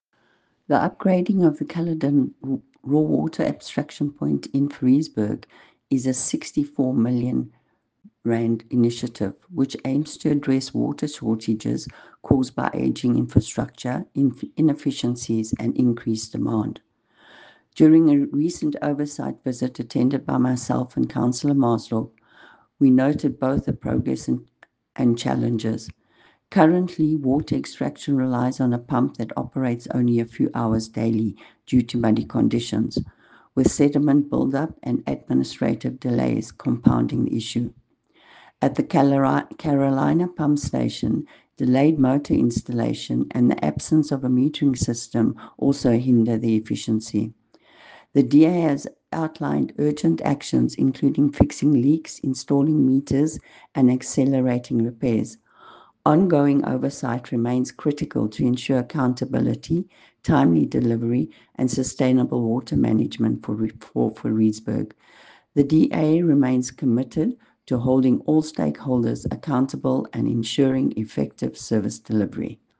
English soundbite by Cllr Irene Rugheimer